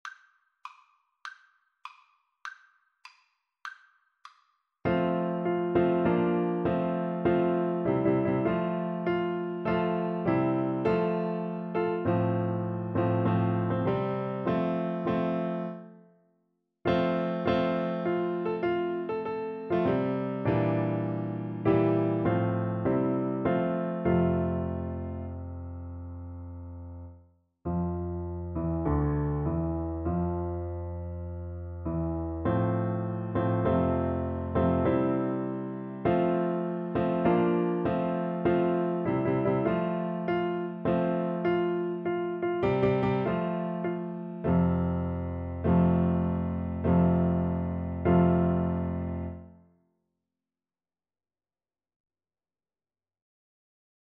2/4 (View more 2/4 Music)
F major (Sounding Pitch) (View more F major Music for Tuba )